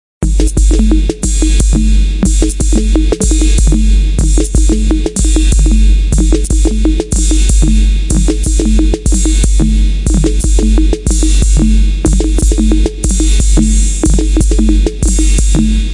部落鼓的节奏 " 部落鼓的节奏03
描述：Midi在键盘上录制并通过Music Maker的虚拟鼓合成器进行处理。十个电子鼓循环中的一个，具有部落节拍和人声。